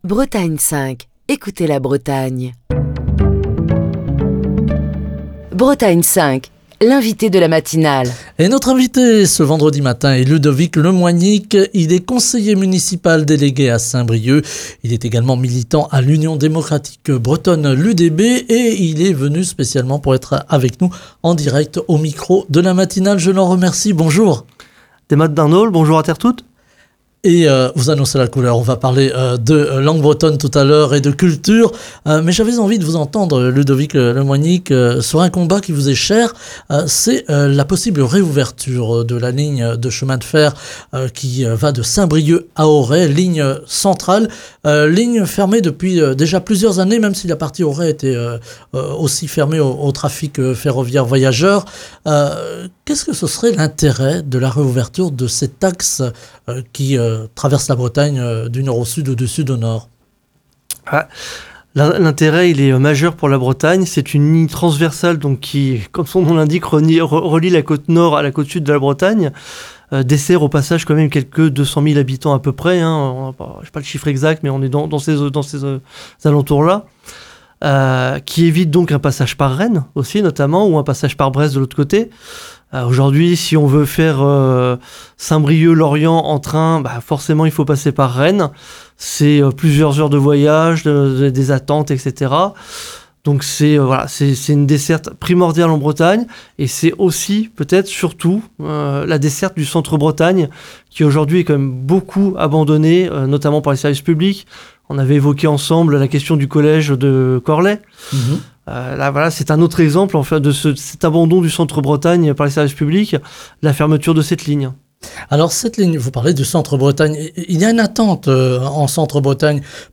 Ludovic Le Moignic, conseiller municipal délégué à Saint-Brieuc et militant de l’Union Démocratique Bretonne (UDB), était l’invité de Bretagne 5 Matin ce vendredi.